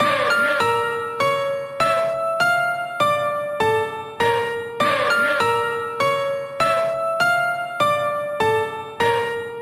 Tag: 100 bpm Rap Loops Piano Loops 1.62 MB wav Key : D